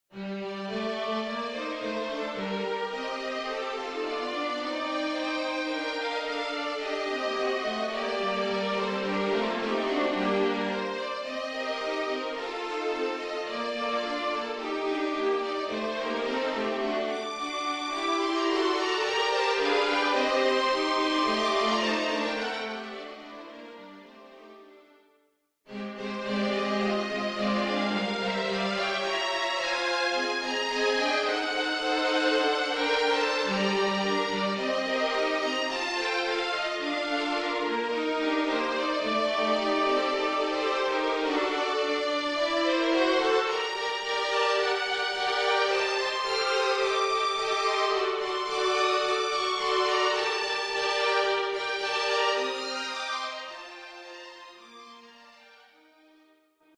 Collection : Cordes (Quatuors)
Sardane pour quatre violons.